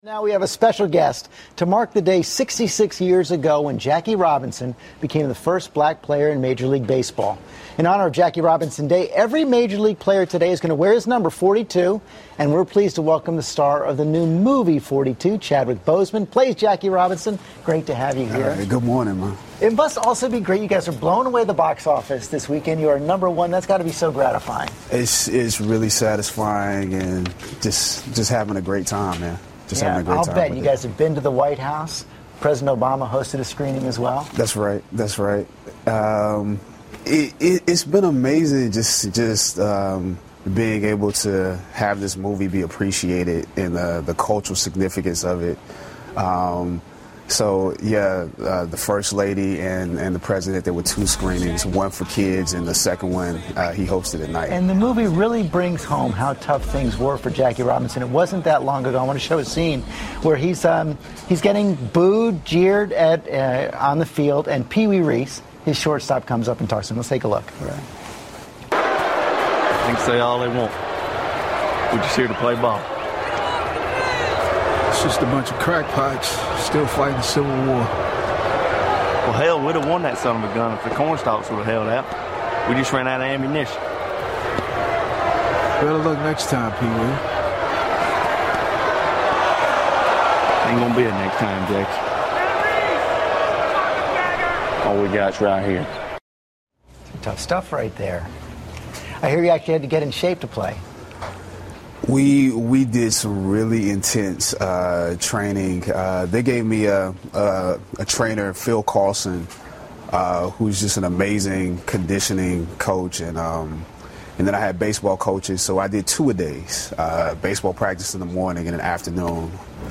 访谈录 2013-04-17&04-19 棒球大联盟传记片《42号》主演专访 听力文件下载—在线英语听力室